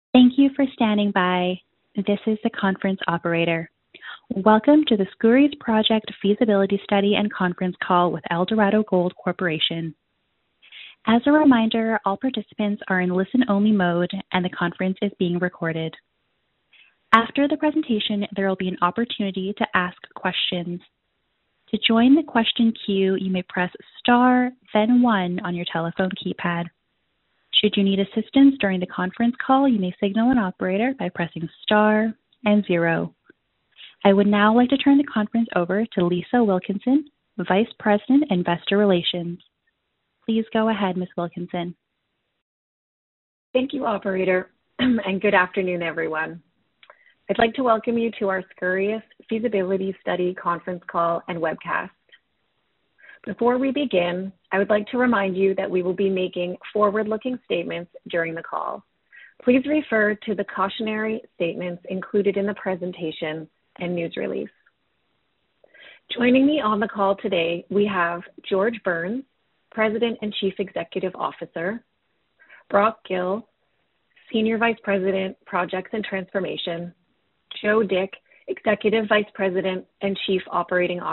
Skouries Project: Feasibility Study Conference Call